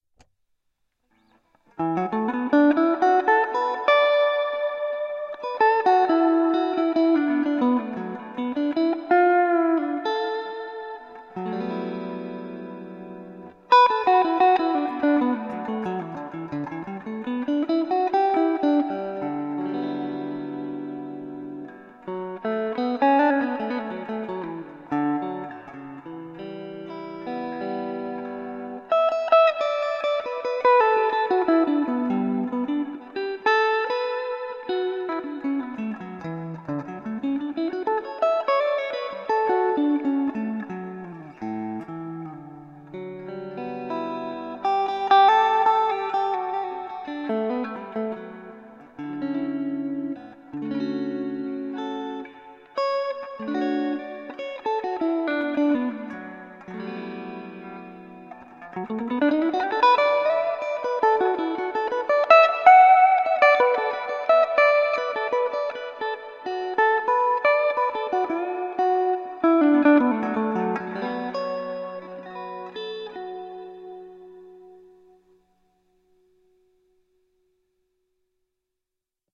Samples: recorded with DR1 pocket recorder about 2 feet away
Neck pickup,  bass/treble middle setting – large room reverb 1/3 depth
large-room-reverb-noodle.mp3